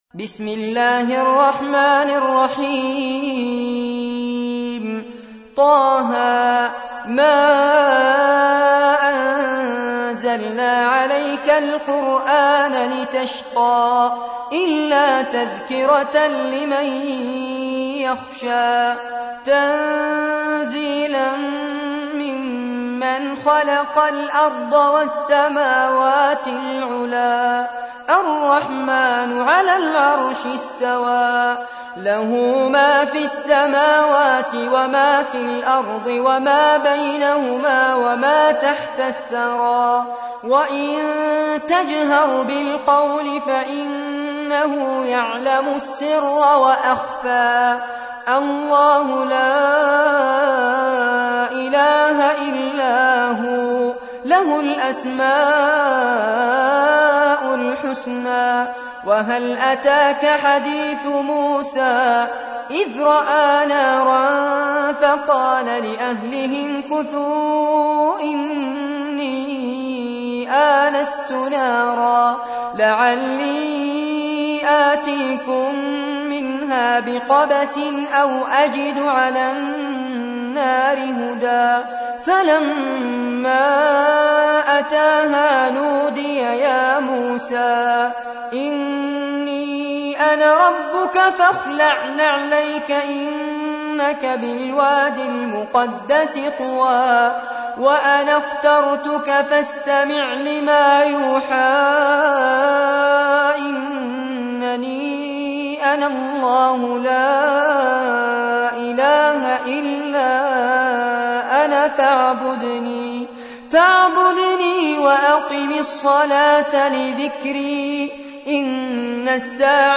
تحميل : 20. سورة طه / القارئ فارس عباد / القرآن الكريم / موقع يا حسين